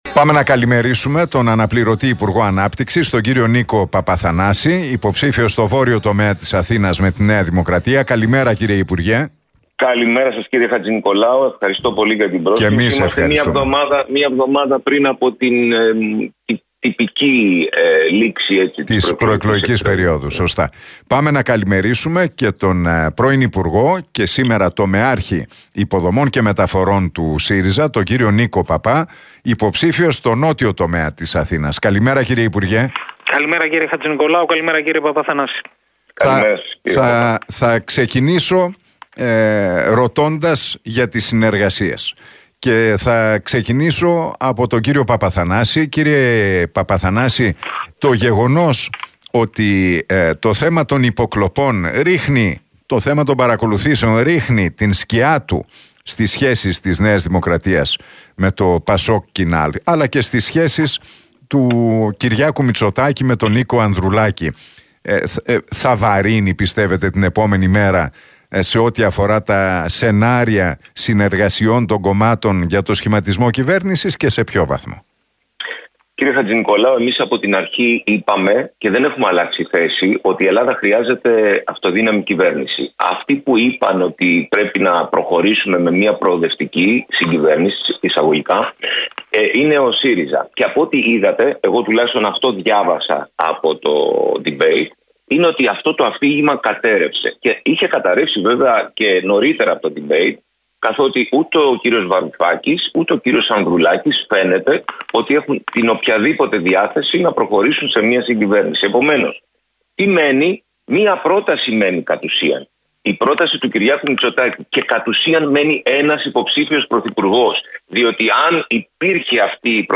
Εκλογές 2023: Debate Παπαθανάση - Παππά στον Realfm 97,8
Τα ξίφη τους διασταύρωσαν στον αέρα του Realfm 97,8 και την εκπομπή του Νίκου Χατζηνικολάου, σε ένα debate ο αναπληρωτής υπουργός Ανάπτυξης και υποψήφιος με